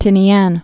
(tĭnē-ăn, tēnē-än)